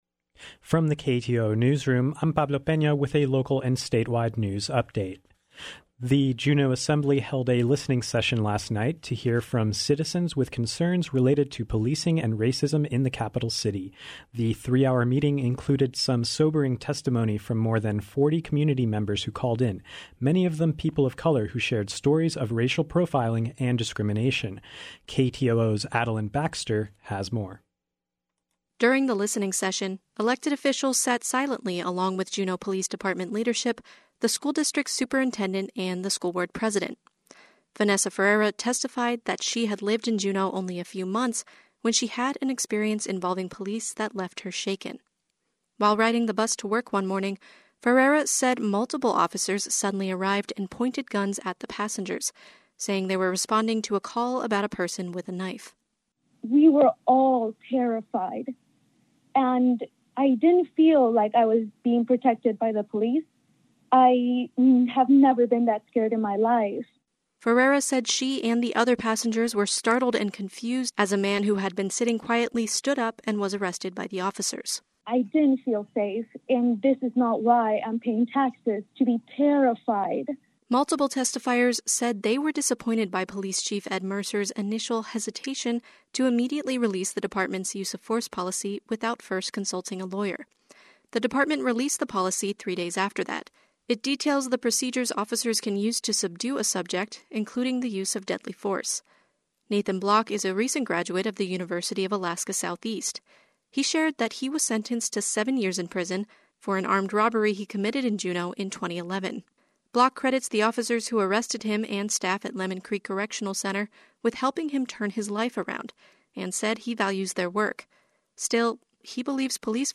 Newscast — Thursday, June 18, 2020